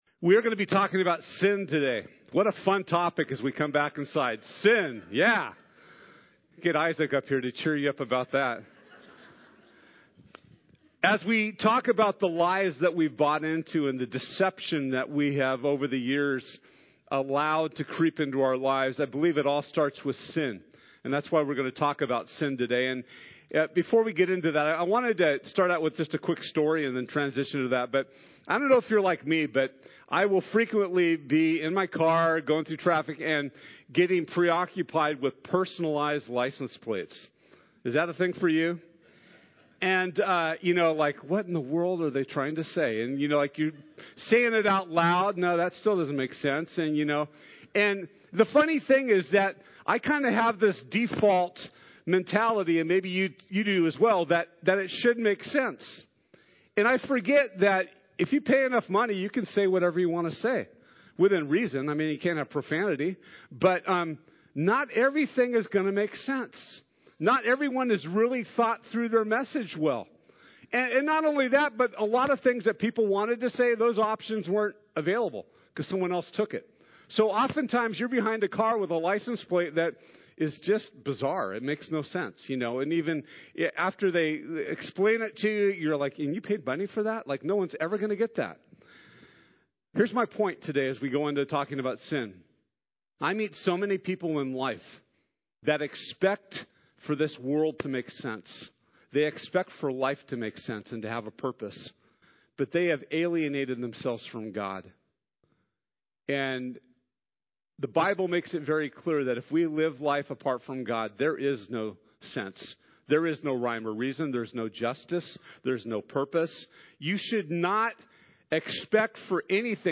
Free Indeed Service Type: Sunday This Sunday